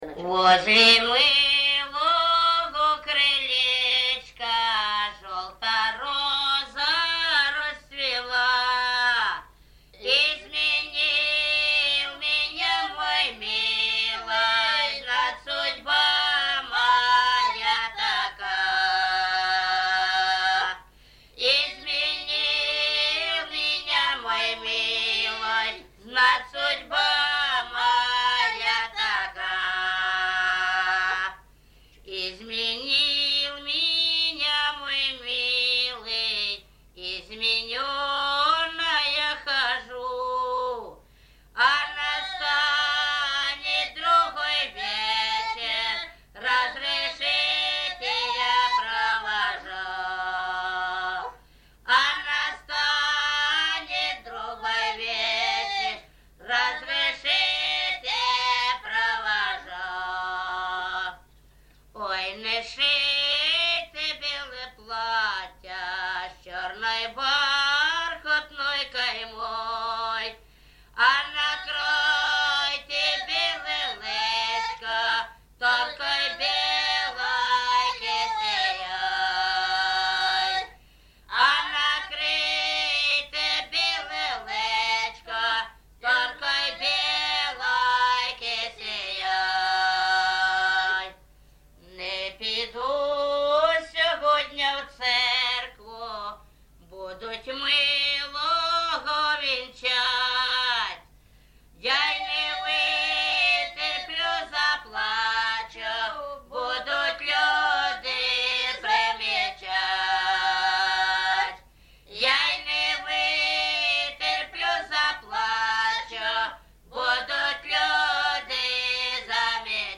ЖанрСучасні пісні та новотвори
Місце записус. Гарбузівка, Сумський район, Сумська обл., Україна, Слобожанщина